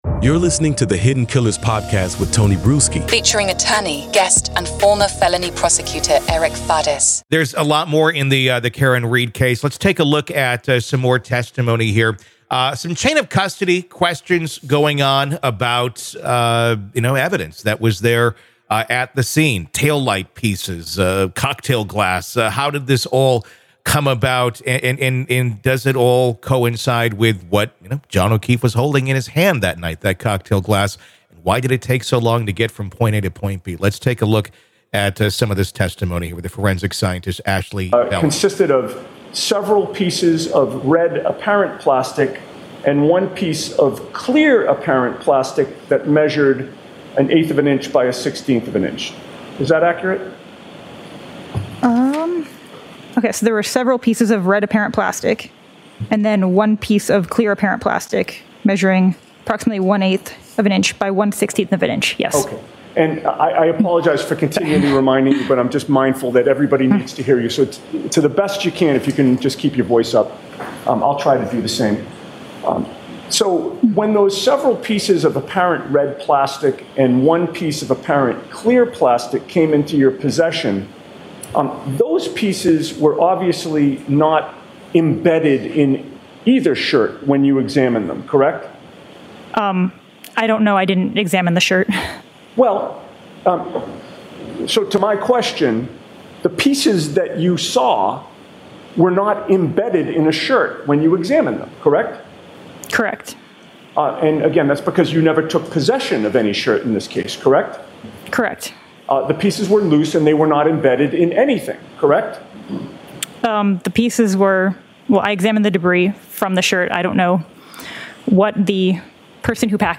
The discussion centers around the chain of custody and the handling of critical evidence, such as plastic and glass fragments associated with the death of John O'Keefe. Questions are raised about the timeline and integrity of the evidence, particularly focusing on whether there has been any tampering or mismanagement. The conversation highlights testimonies from forensic scientists and scrutinizes the plausibility of the defense's arguments regarding evidence handling and the presence of glass shards on Read's vehicle.